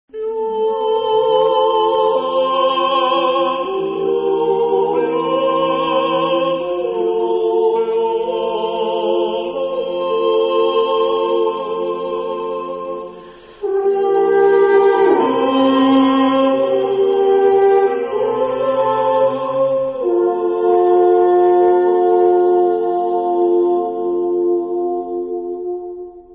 Jodel-Alphorn.mp3